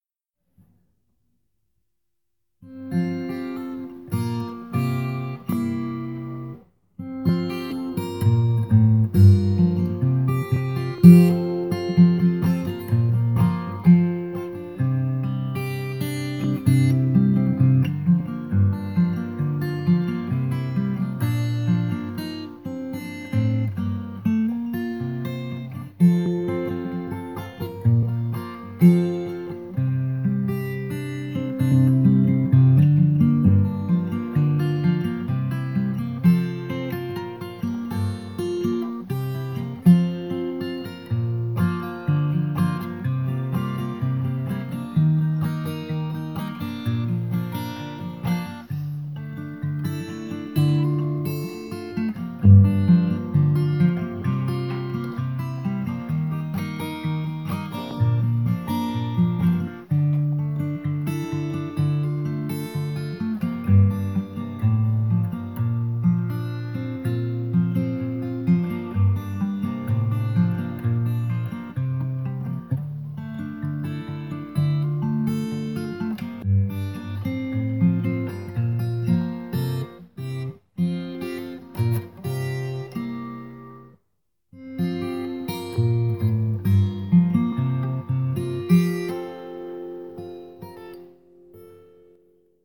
Listen to the Small Jumbo here! Small Jumbo Audio Clip (MP3)